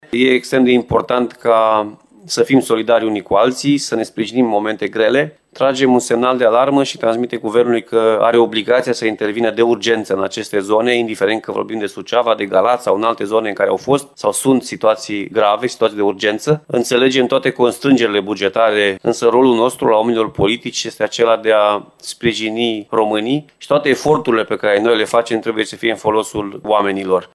Liderul administraţiei timişene, Alfred Simonis, face apel la solidaritate naţională şi cere Guvernului intervenţii urgente în zonele afectate de inundaţii, în ciuda constrângerilor bugetare.